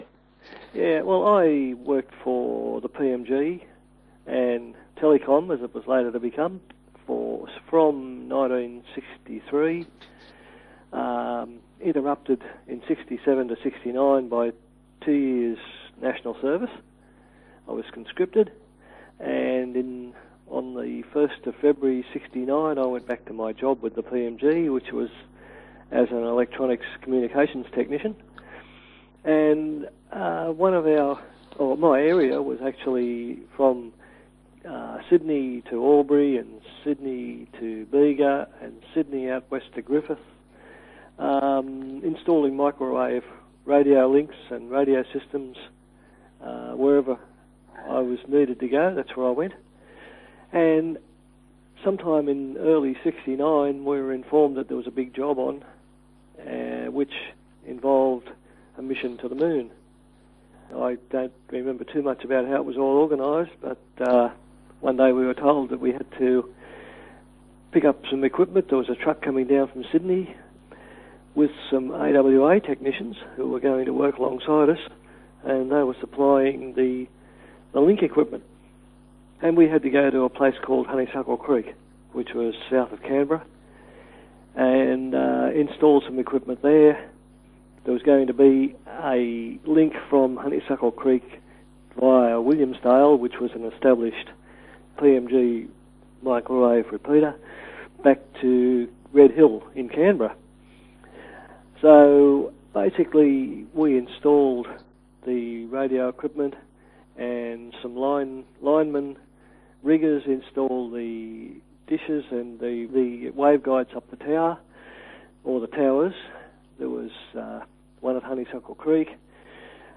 Listen to a 13 minute interview